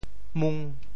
们（們） 部首拼音 部首 亻 总笔划 5 部外笔划 3 普通话 mén 潮州发音 潮州 mung5 文 中文解释 们 <助> 用在代词或指人的名词后面,表示复数。